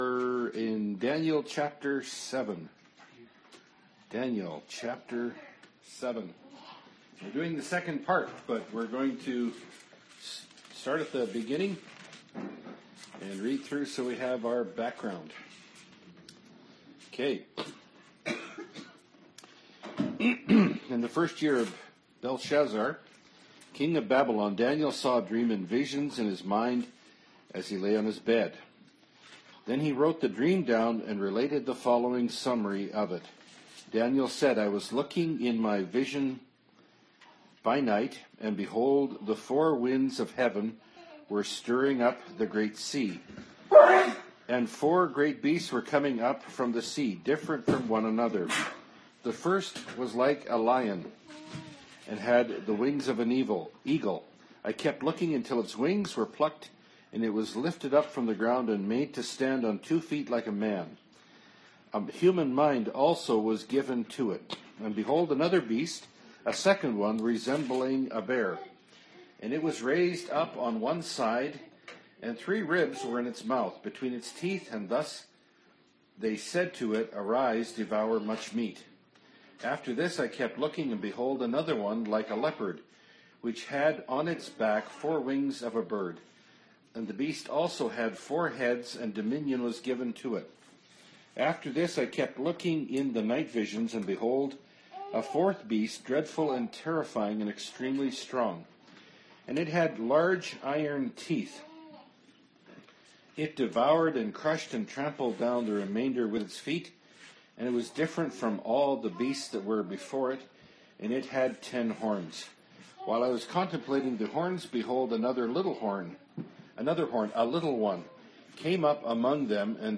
Bible Study – Daniel 7 – Part 2 of 3 (2017)